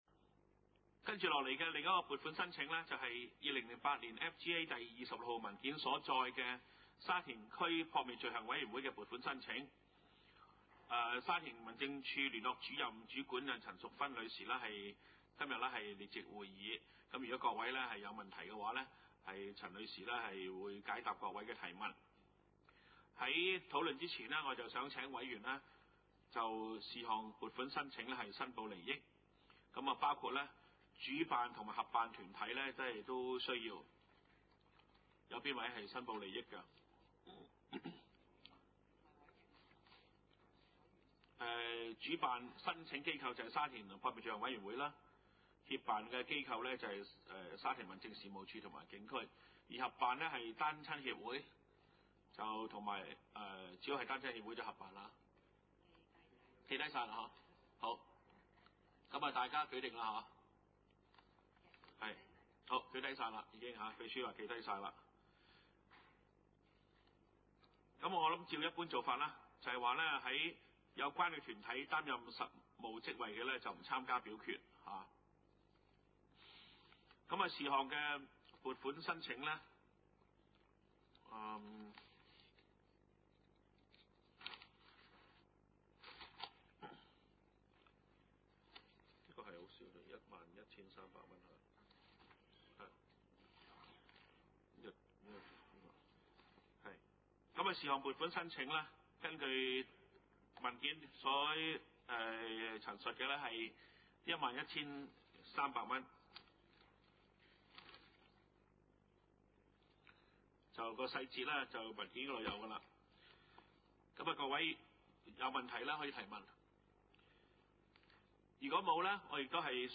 二零零八年第二次會議
: 沙田區議會會議室